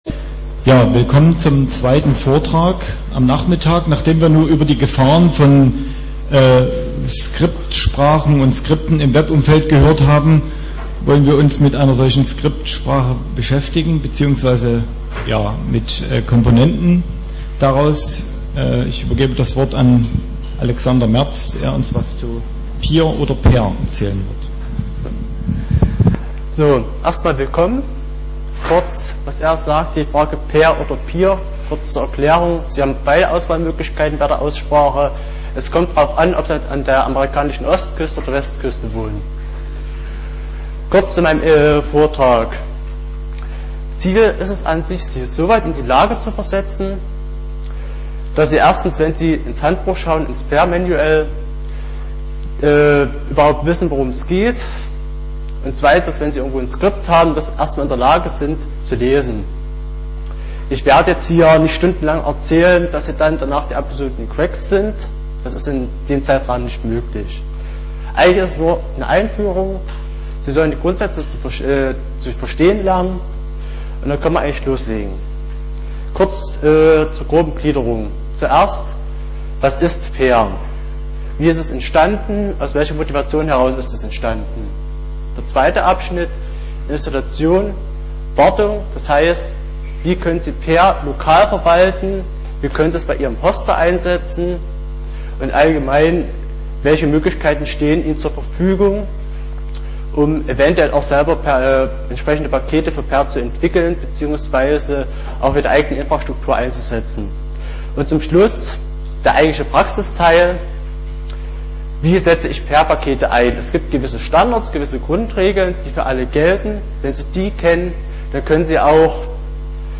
5. Chemnitzer Linux-Tag
Samstag, 15:00 Uhr im Raum V2 - WWW-Scripting